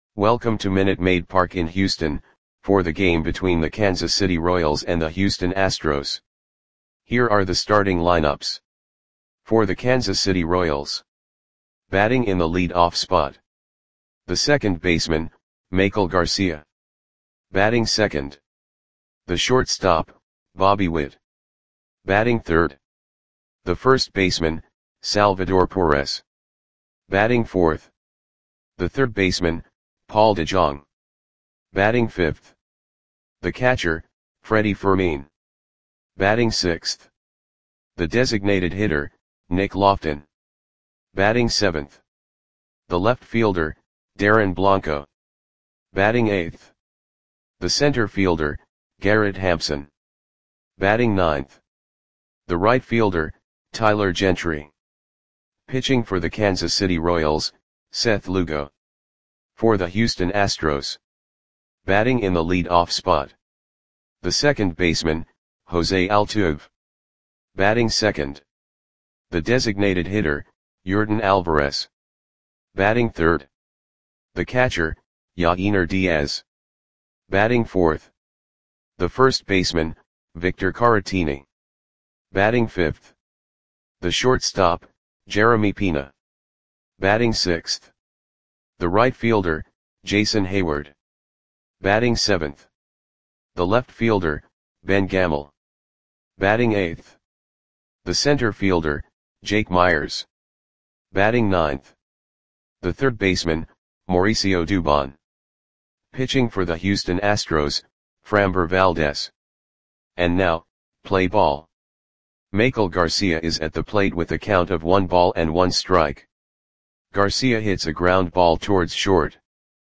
Audio Play-by-Play for Houston Astros on August 30, 2024
Click the button below to listen to the audio play-by-play.